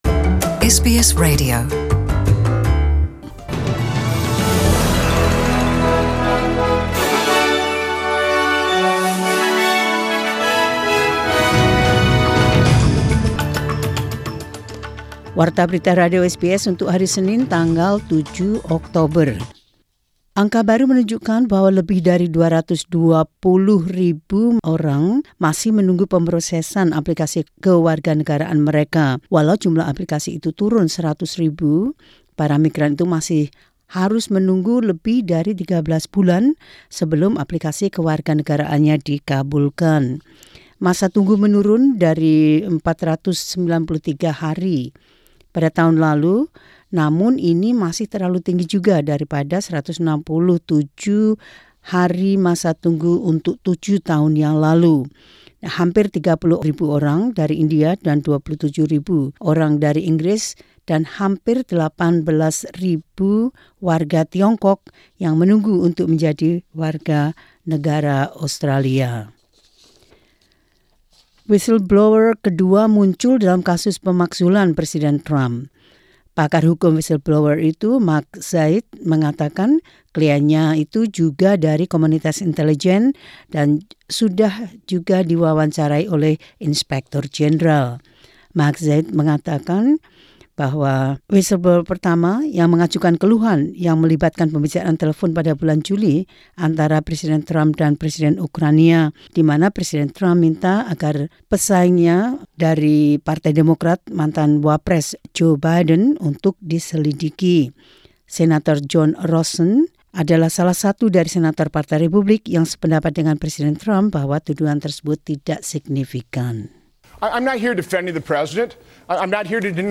Warta Berita Radio SBS 7 Okt 2019 dalam Bahasa Indonesia.